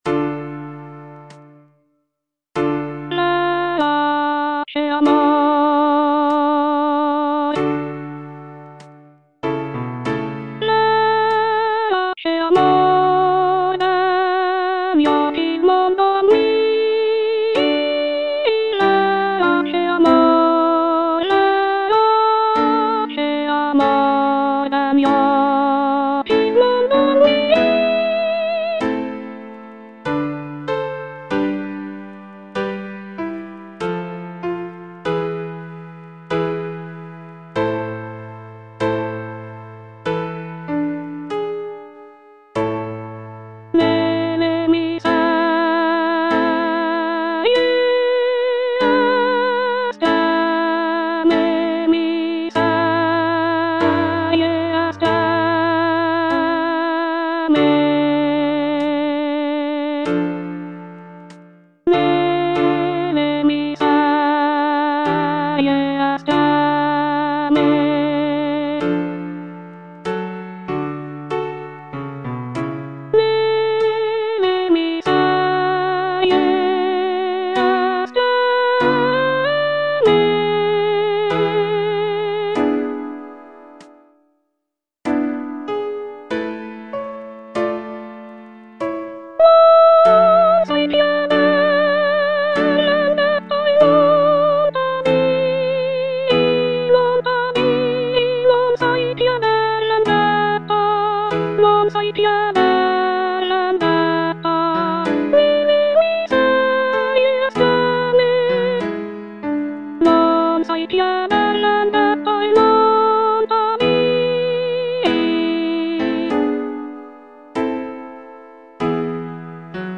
C. MONTEVERDI - LAMENTO D'ARIANNA (VERSION 2) Coro IV: Verace amor (soprano I) (Voice with metronome) Ads stop: auto-stop
The music is characterized by its expressive melodies and poignant harmonies, making it a powerful and moving example of early Baroque vocal music.